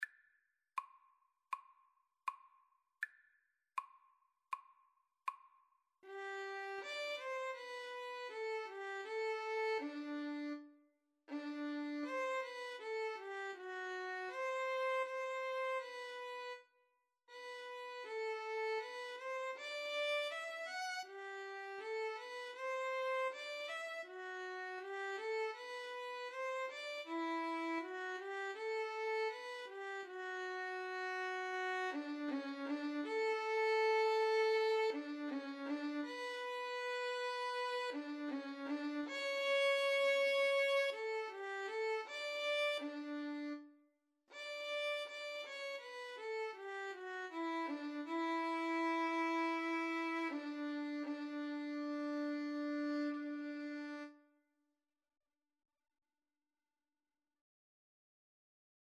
Largo =80
Violin Duet  (View more Easy Violin Duet Music)